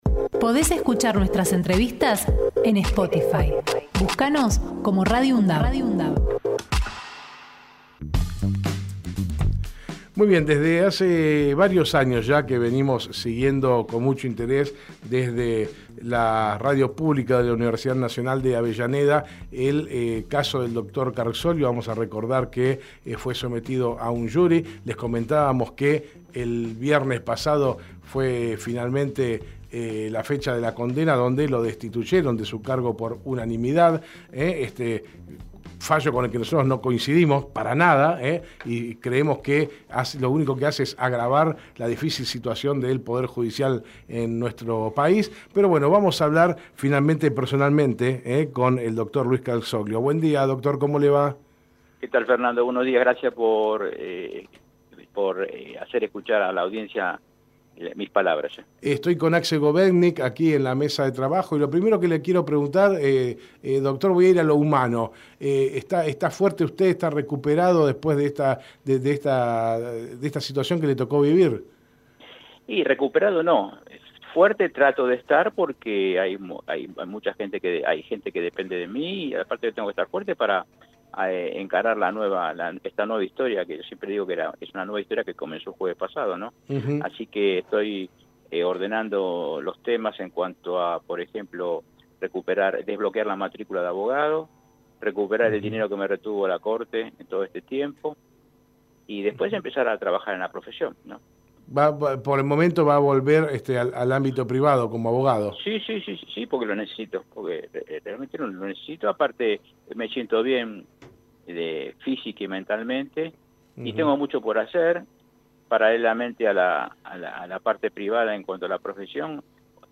Luis Carzoglio en Hacemos PyE Texto de la nota: Compartimos la entrevista realizada en Hacemos PyE con el Dr. Luis Carzoglio, ex juez de Garantías de Avellaneda. Conversamos sobre su destitución e inhabilitación.